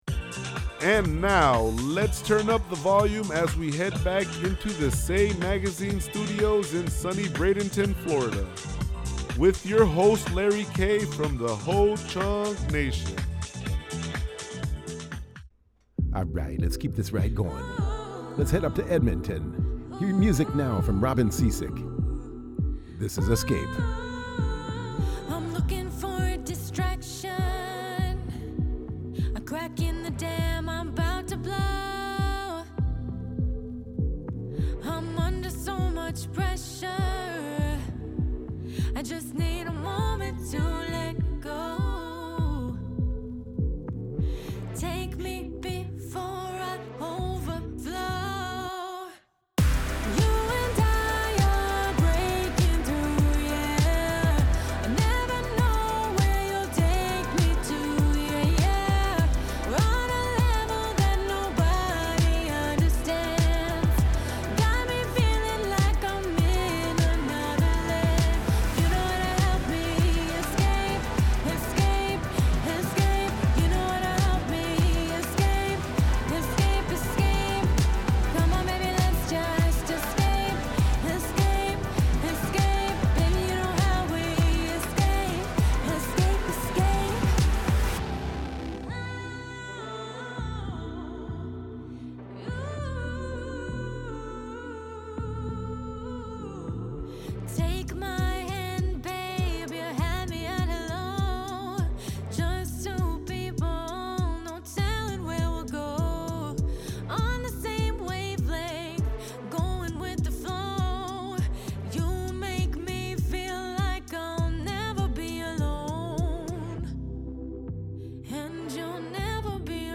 Spinning the Hottest Indigenous Hits and Artist Interviews